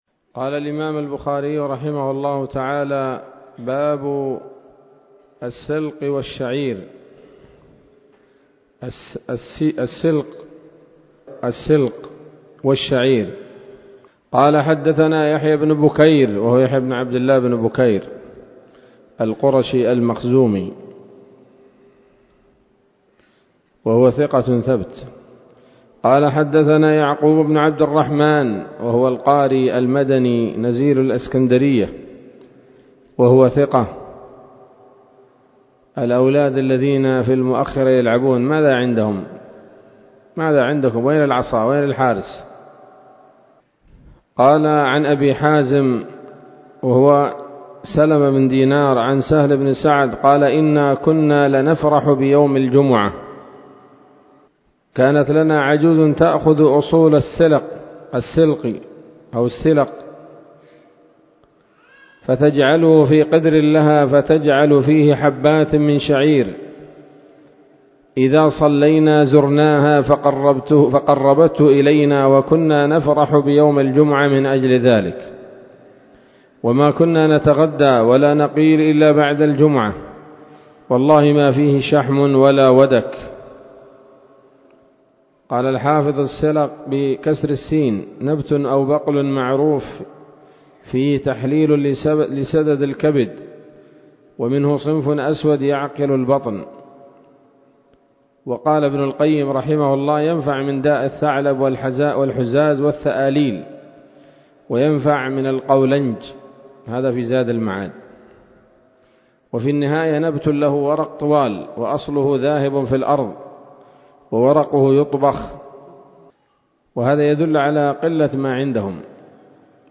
الدرس الثالث عشر من كتاب الأطعمة من صحيح الإمام البخاري